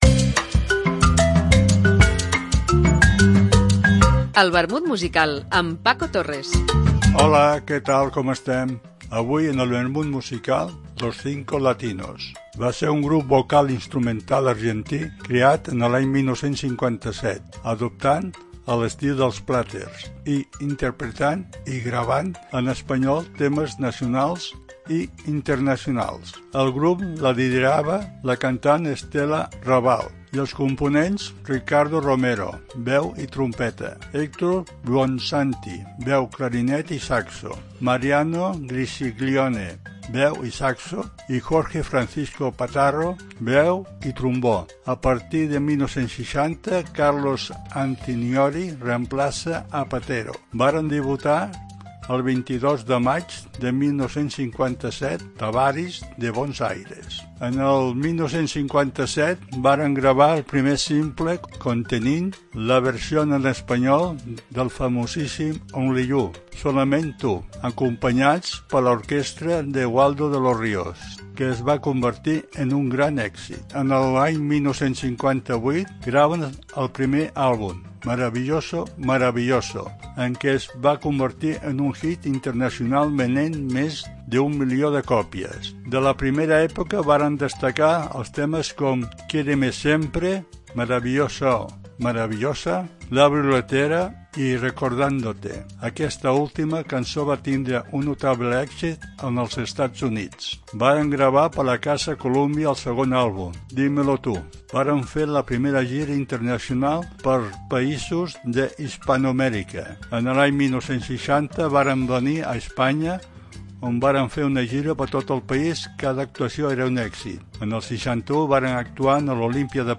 trios...etc. Una apunts biogràfics acompanyats per una cançó.